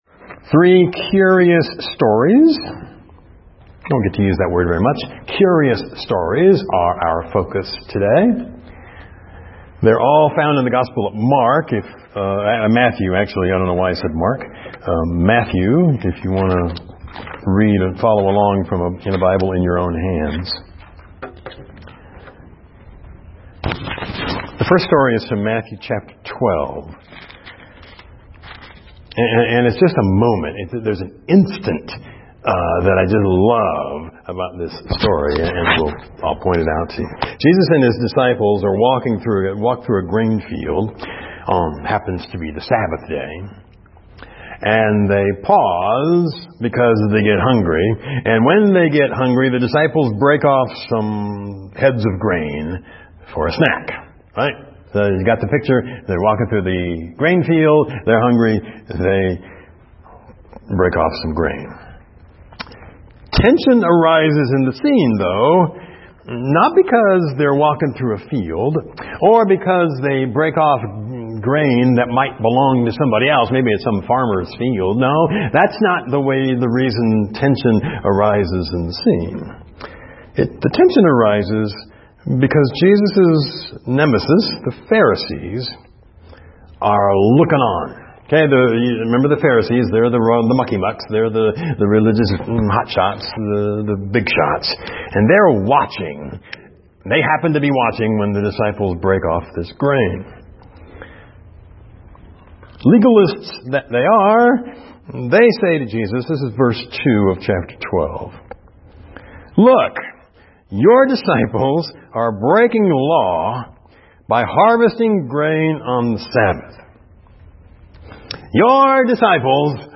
FCCEM Sermon Audio Files - First Christian Church of East Moline